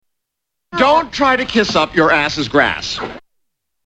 Category: Television   Right: Personal
Tags: 3rd Rock from the Sun TV sitcom Dick Solomon John Lithgow Dick Solomon clips